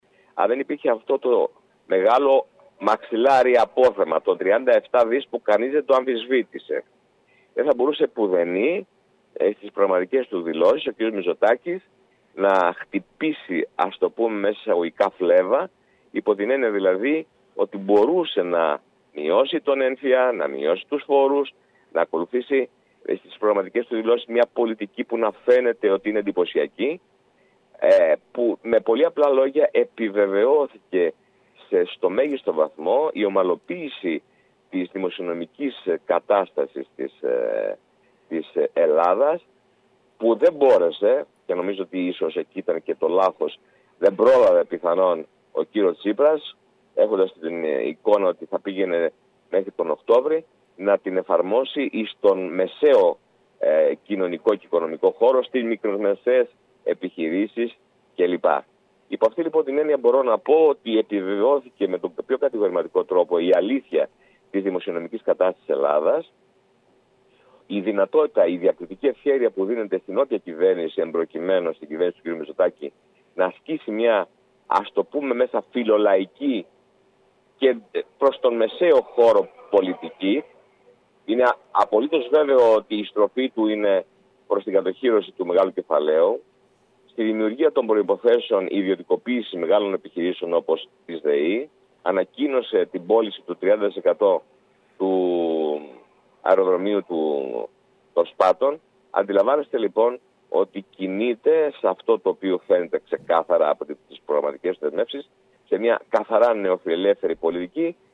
Τις προγραμματικές δηλώσεις της κυβέρνησης σχολίασαν μιλώντας στην ΕΡΤ Κέρκυρας, οι βουλευτές του νησιού Στ. Γκίκας, Αλ. Αυλωνίτης και Δ. Μπιάγκης.